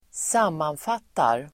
Uttal: [²s'am:anfat:ar]